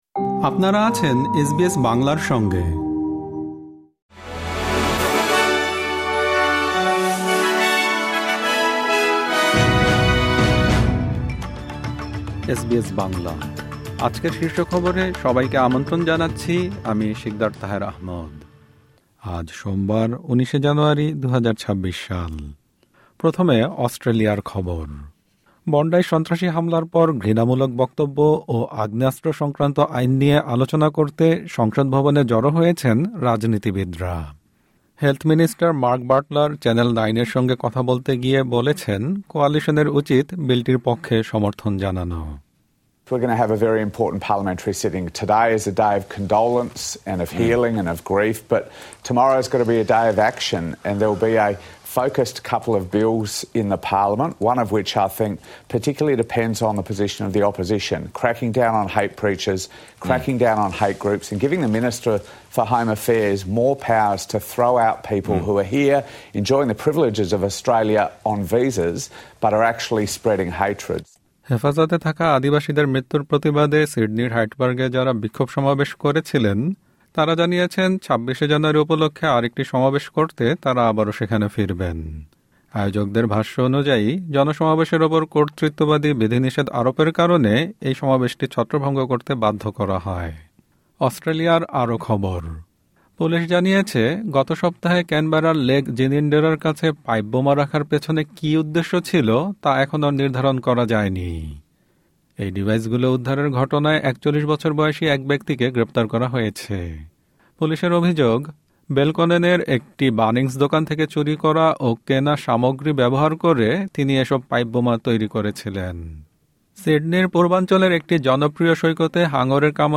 এসবিএস বাংলা শীর্ষ খবর: ঘৃণামূলক বক্তব্য ও আগ্নেয়াস্ত্র আইন নিয়ে আলোচনা করতে সংসদকে আবারও আহ্বান